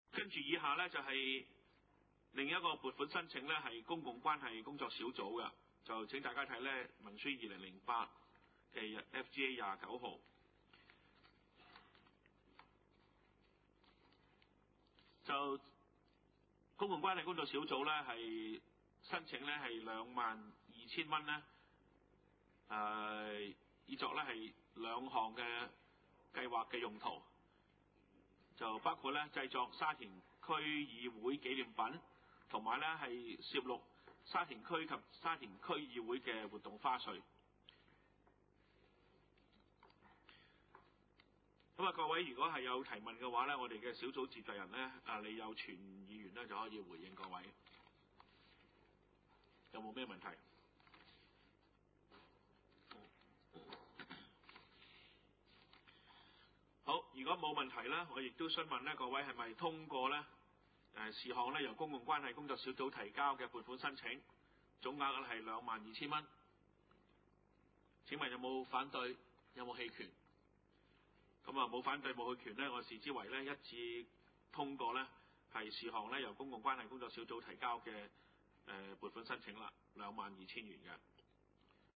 二零零八年第二次會議
: 沙田區議會會議室